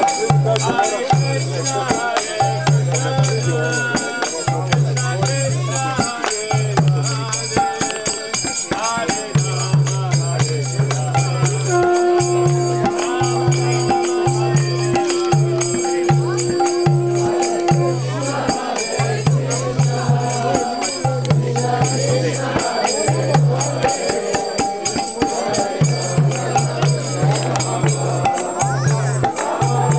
Hare-Krishna-Umzug - 16:33 (47/47) O-Ton: Html5-Audio wird von Ihrem Browser nicht unterstützt; verwenden Sie eine aktuelle Version von Firefox, Opera oder Chrome.
Download audio file / Tondatei herunterladen: Chanten bei Hare-Krishna-Prozession / chanting at Hare-Krishna-procession (Die Tondatei können Sie mit dem VLC-Player abspielen.